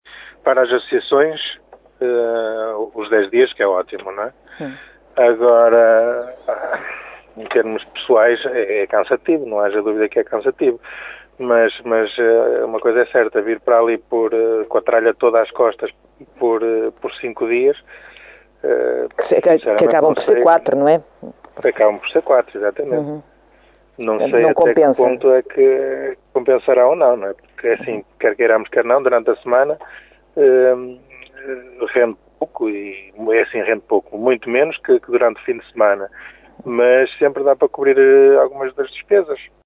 A Rádio Caminha saiu à rua para ouvir comerciantes e instituições do concelho e percebeu que as opiniões dividem-se, mas a maioria dos auscultados não concorda com a decisão do presidente da Câmara.